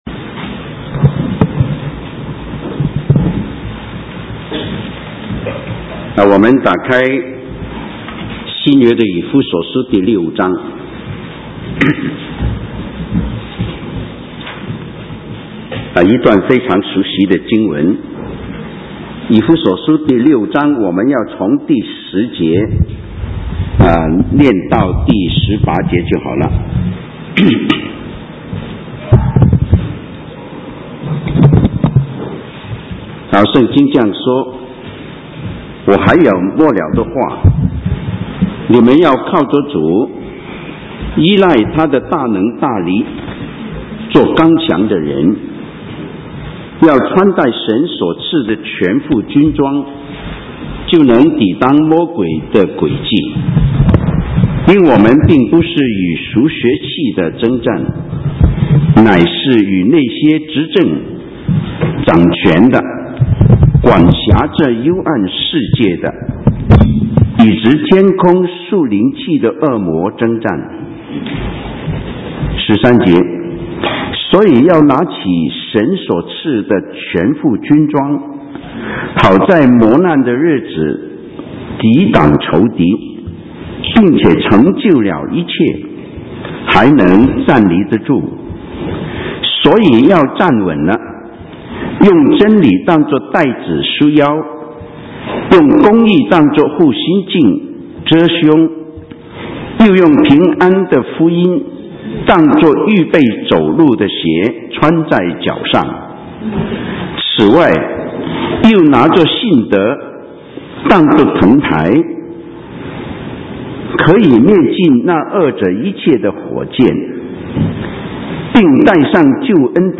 神州宣教--讲道录音 浏览：属灵的争战 (2011-11-20)